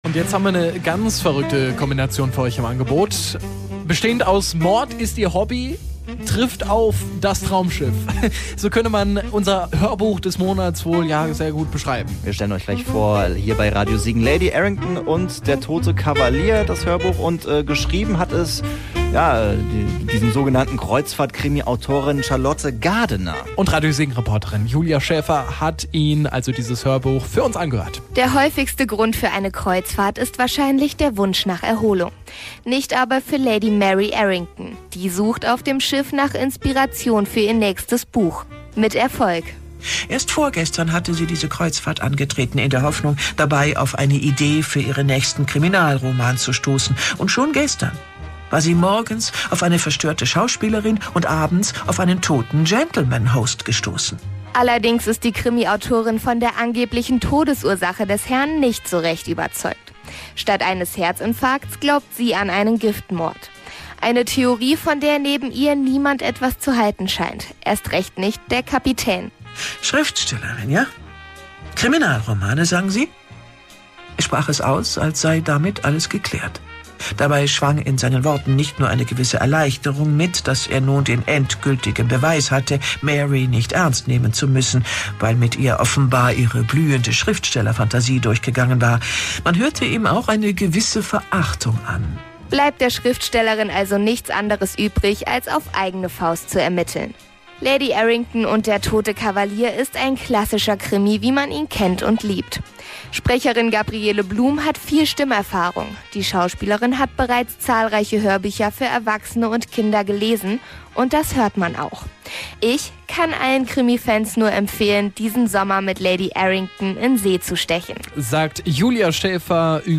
hoerbuch-des-monats-august.mp3